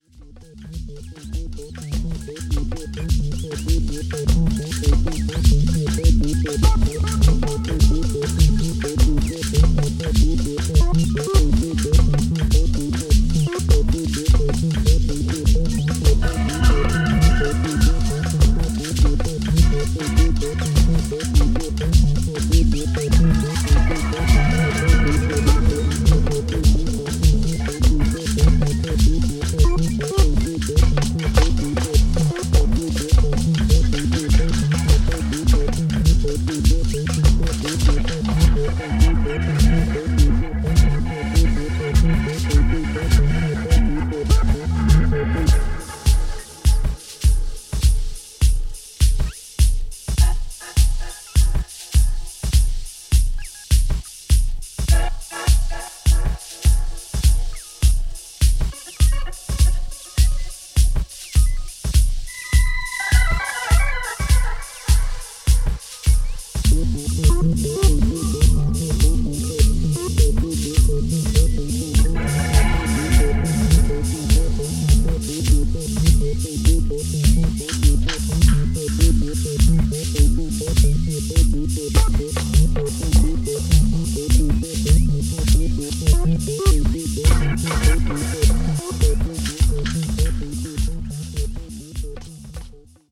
supplier of essential dance music
Electronix House Dub Ambient